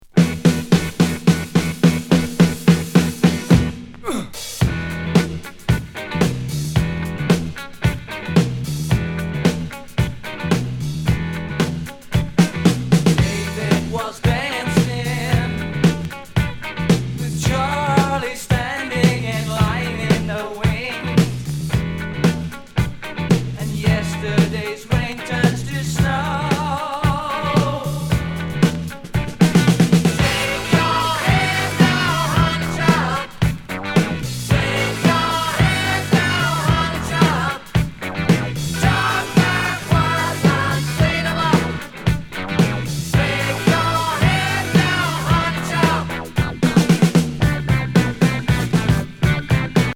UKグラム・ロック・グループのディスコ・ロッキン・ナンバー